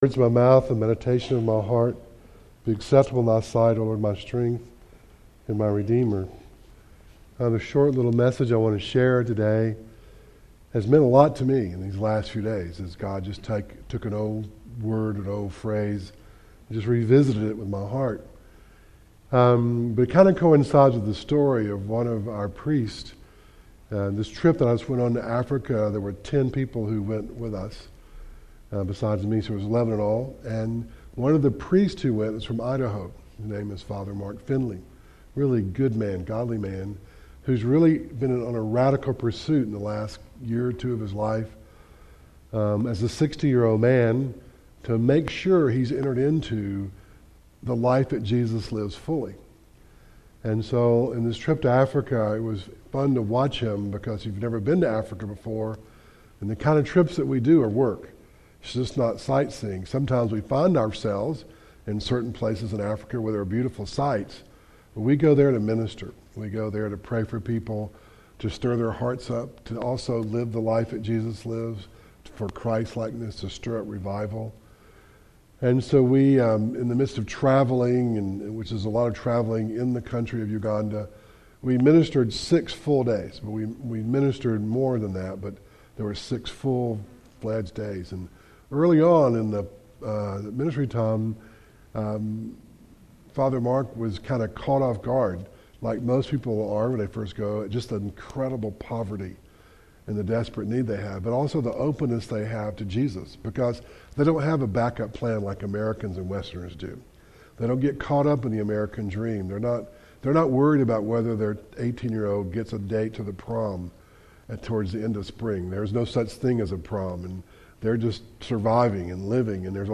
Ephesians 3:14-19 Service Type: Devotional